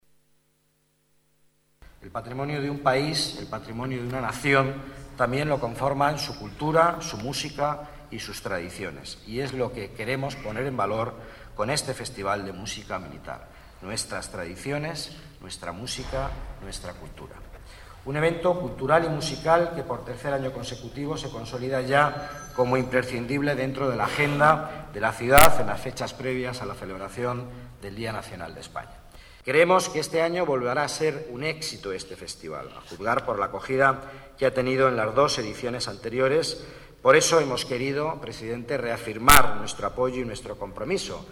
Nueva ventana:Declaraciones del delegado de Economía, Empleo y Participación Ciudadana, Miguel Ángel Villanueva: Música Militar en el Palacio Real